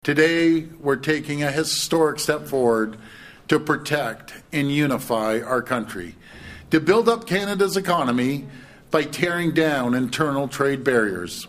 That was Premier Doug Ford yesterday as his government introduced legislation to loosen restrictions and signed agreements with Nova Scotia and New Brunswick recognizing each other’s goods and workers.